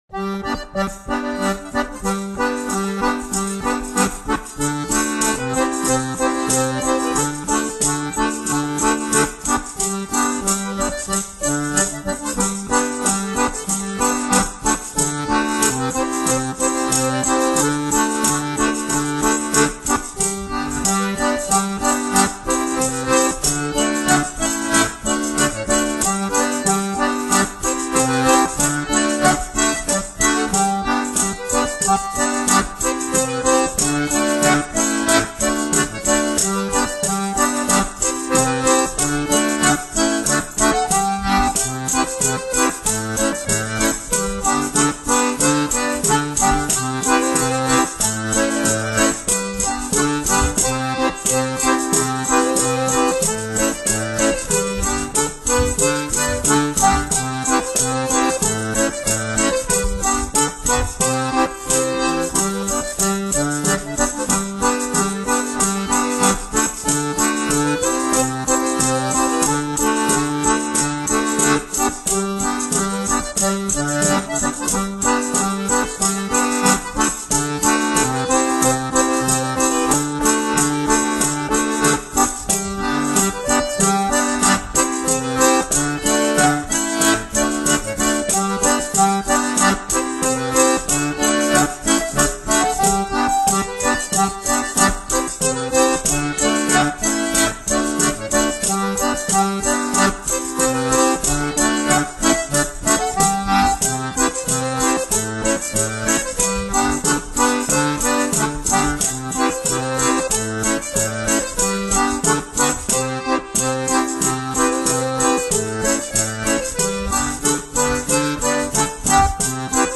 play Play Lower Quality.